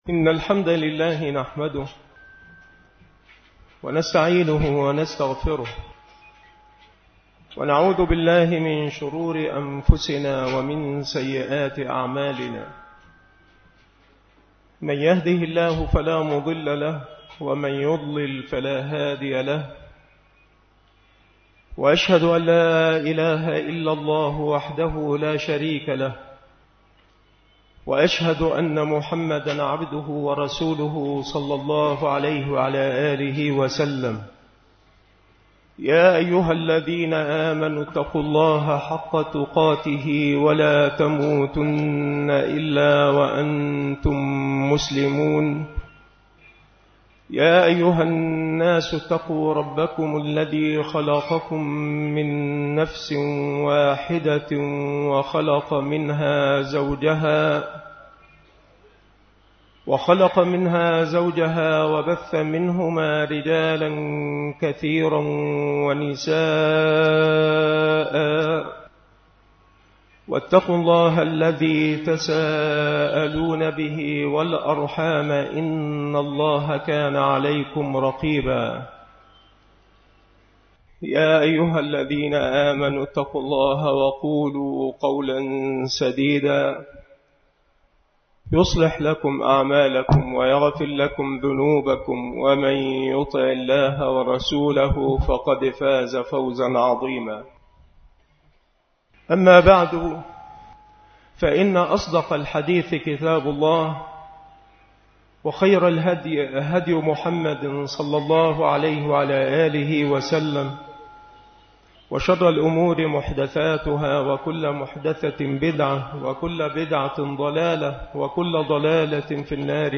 مكان إلقاء هذه المحاضرة بمسجد صلاح الدين بمدينة أشمون - محافظة المنوفية